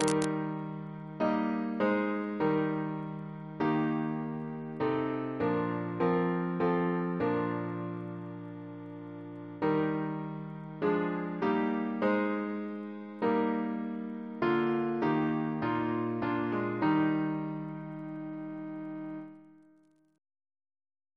Double chant in E♭ Composer: John Robinson (1682-1762), Organist of Westminster Abbey Reference psalters: ACB: 14; ACP: 42; CWP: 155; H1982: S178; PP/SNCB: 99; RSCM: 113